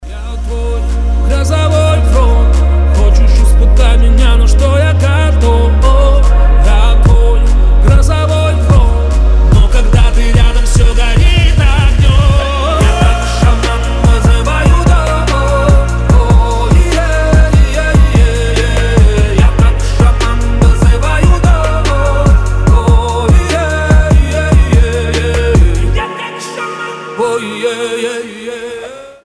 • Качество: 320, Stereo
атмосферные
красивый мужской голос